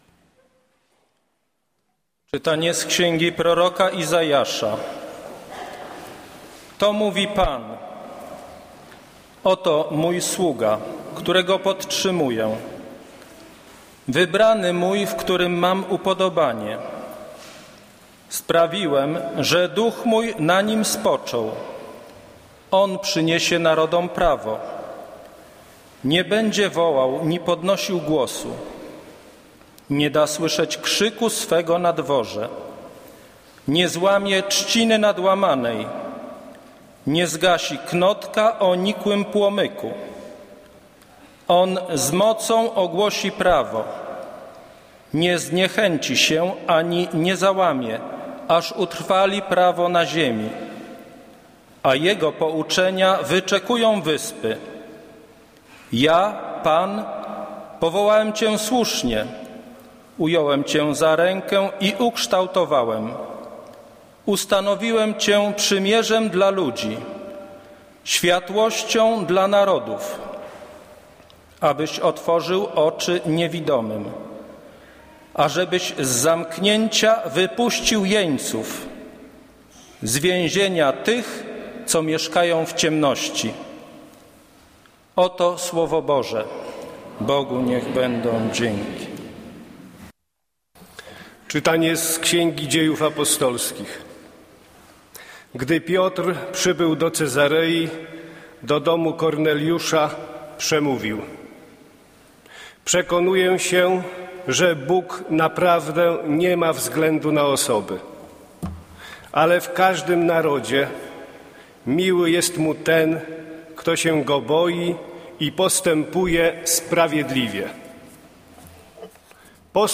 Kazanie z 12 stycznia 2014r.
Piotra Pawlukiewicza // niedziela, godzina 15:00, kościół św. Anny w Warszawie « Kazanie z 1 grudnia 2013r.